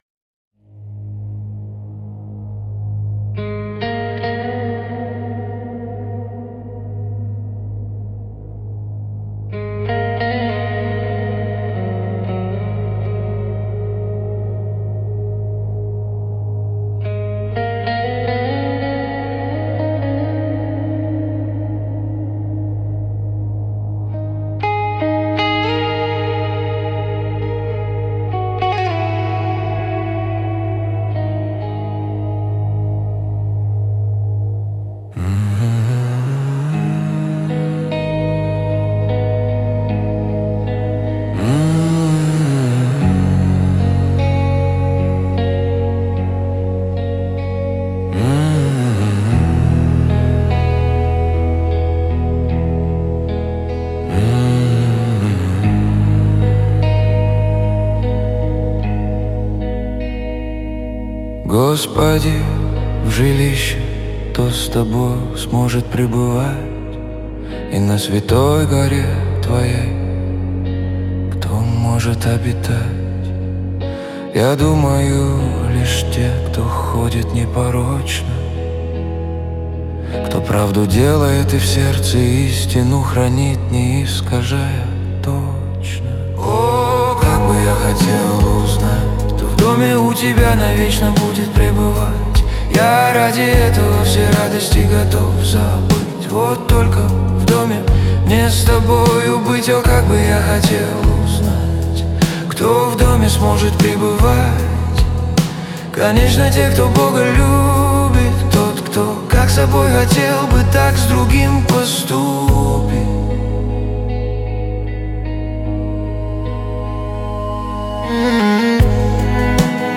241 просмотр 926 прослушиваний 123 скачивания BPM: 78